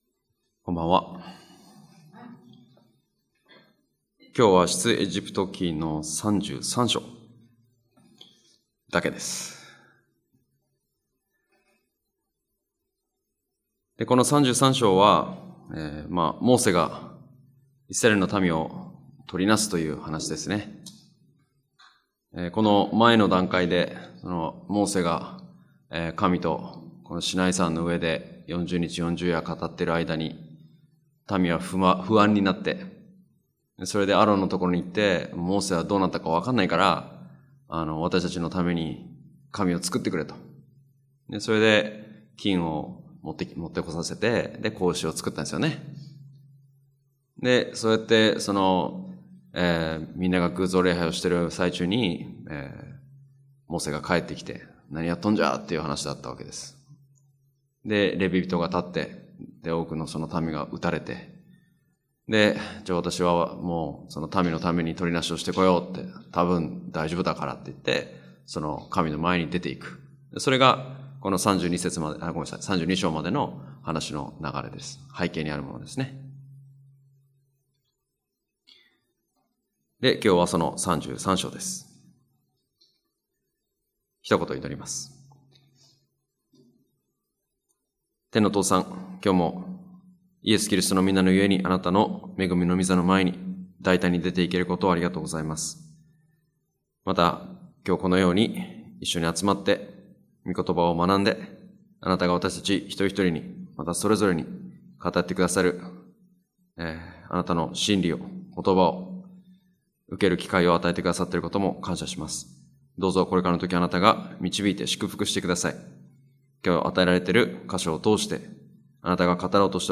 木曜バイブルスタディ：出エジプト記
礼拝やバイブル・スタディ等でのメッセージを聞くことができます。